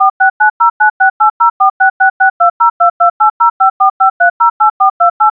Dial Tones
file that sounds like sombody pressing a bunch of numbers into a phone.
Doing this we’ll find that the noises are encoded using a protocal called DTMF.